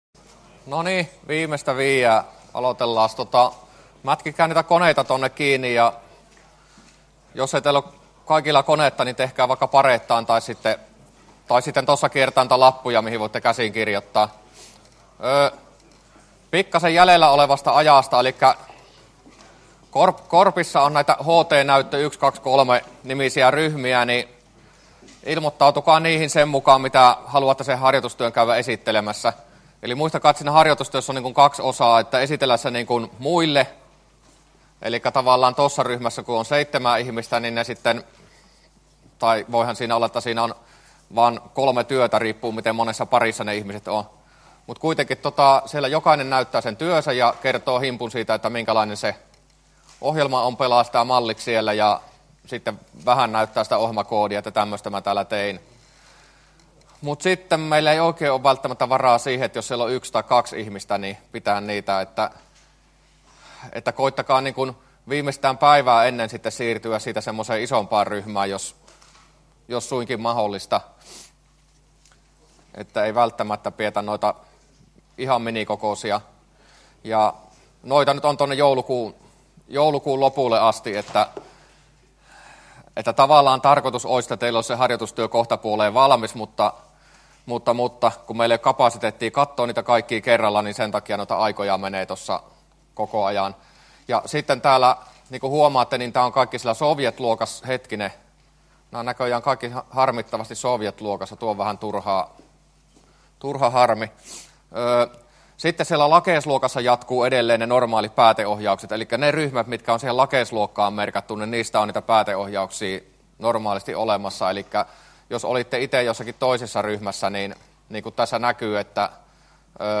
luento24a